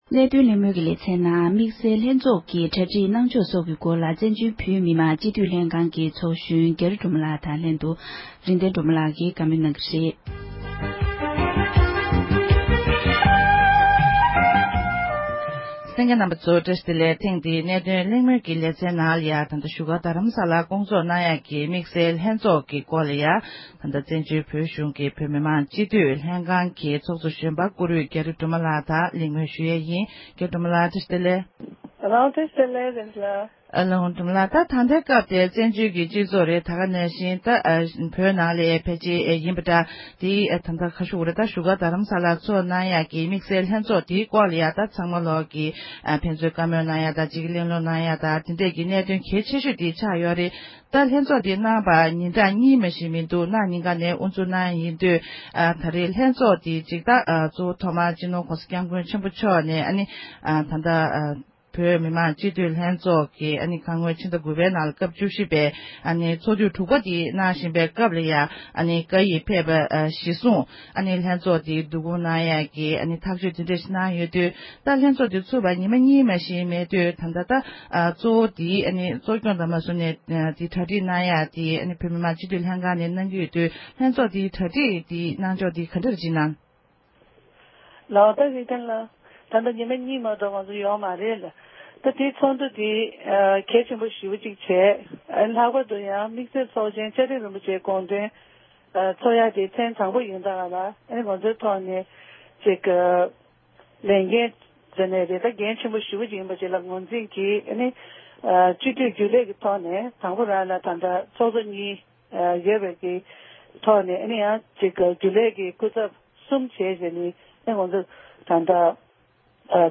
༄༅༎ཐེངས་འདིའི་གནད་དོན་གླེང་མོལ་གྱི་ལེ་ཚན་ནང་དུ་བོད་མི་མང་སྤྱི་འཐུས་ཚོགས་གཞོན་རྒྱ་རི་སྒྲོལ་མ་ལགས་ནས་བདུན་ཕྲག་རྗེས་མའི་ནང་འཚོགས་རྒྱུའི་བཙན་བྱོལ་བོད་གཞུང་མང་གི་དམིགས་བསལ་ཚོགས་འདུ་དང་འབྲེལ་བའི་གནས་ཚུལ་སྐོར་གླེང་མོལ་གནང་བར་གསན་རོགས་གནང༌༎